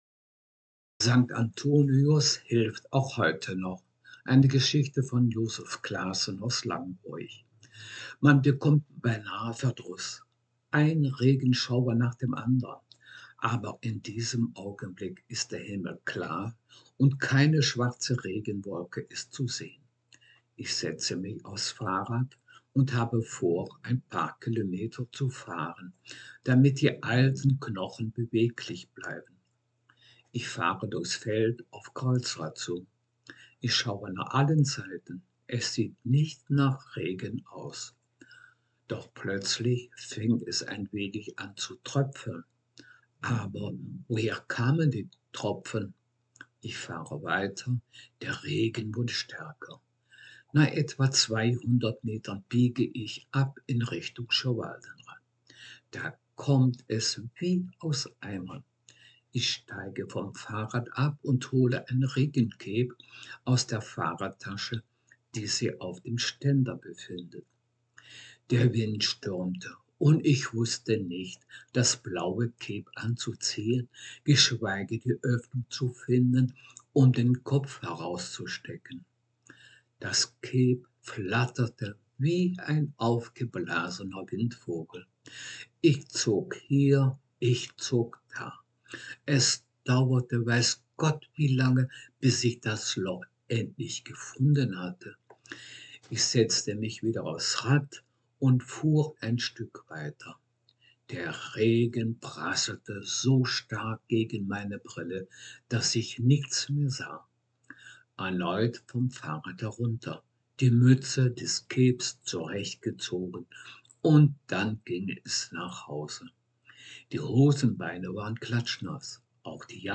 Text hochdeutsch